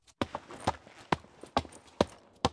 shared_walk.wav